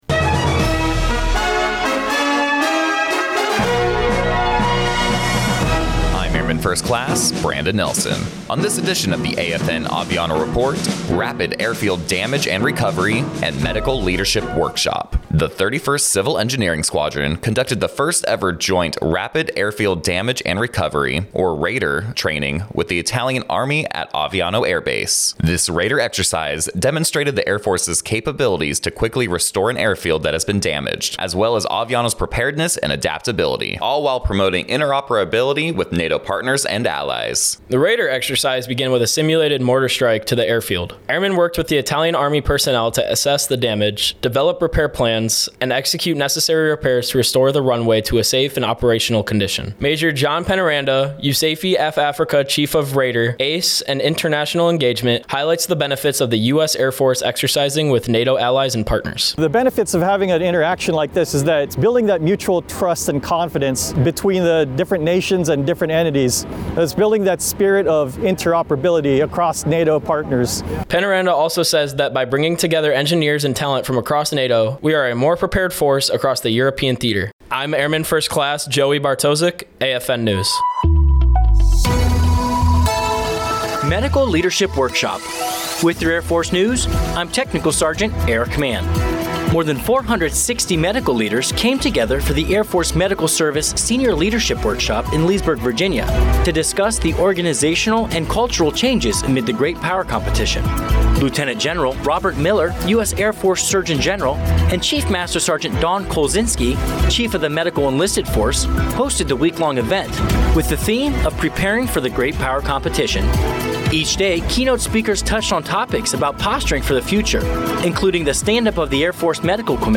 American Forces Network (AFN) Aviano radio news reports on the Rapid Airfield Damage and Recovery training conducted by the 31st Civil Engineering Squadron and Italian Army at Aviano Air Base. This first of its kind joint training demonstrates the Air Force’s ability to quickly repair damaged airfields.